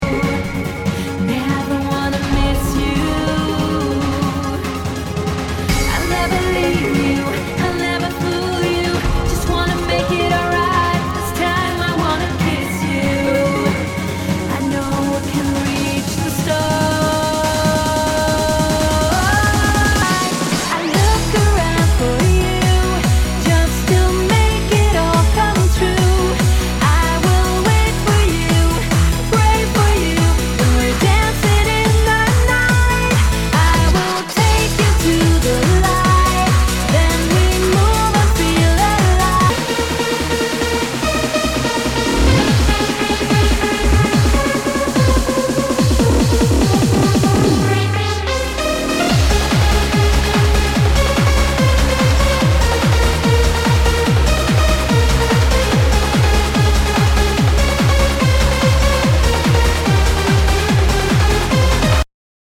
HOUSE/TECHNO/ELECTRO
ナイス！ヴォーカル・トランス！
類別 Trance